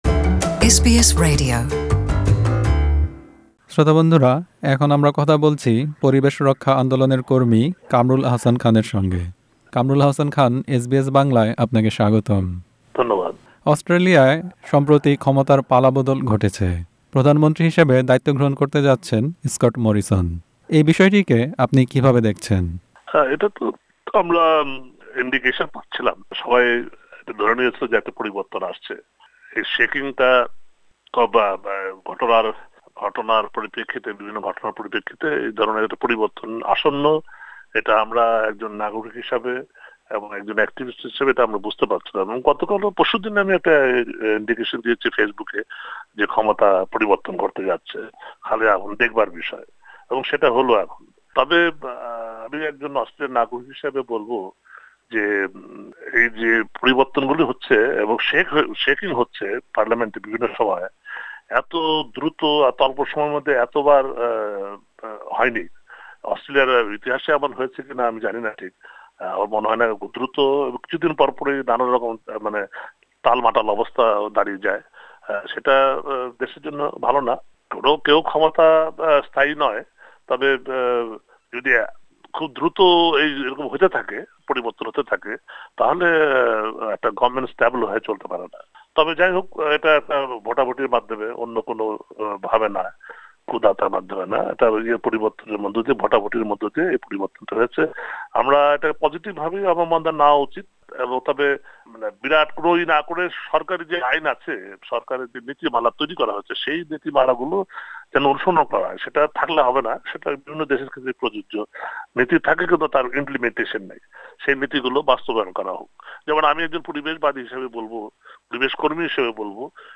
Leadership spill: Interview